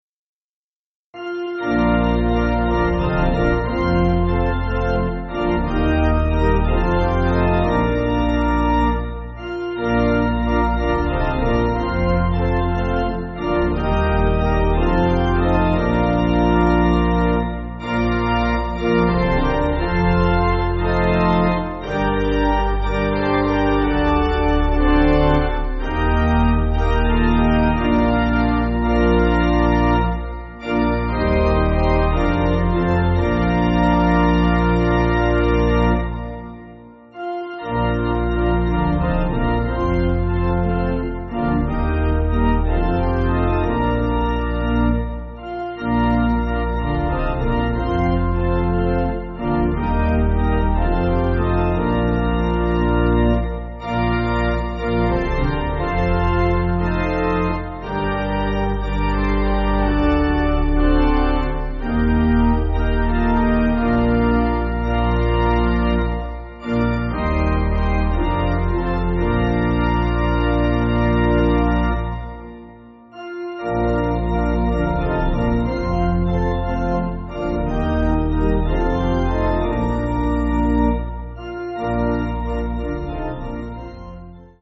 Organ
(CM)   4/Bb